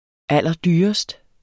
Udtale [ ˈalˀʌˈdyːʌsd ]